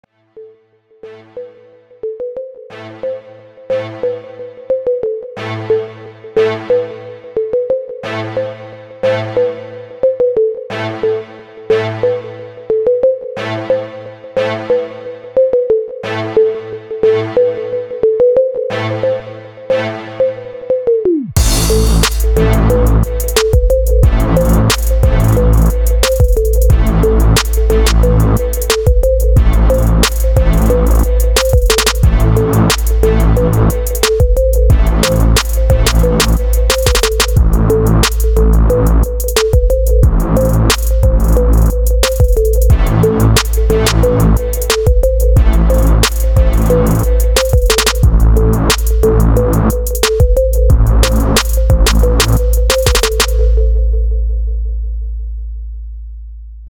Скачать Минус
Стиль: Break Beat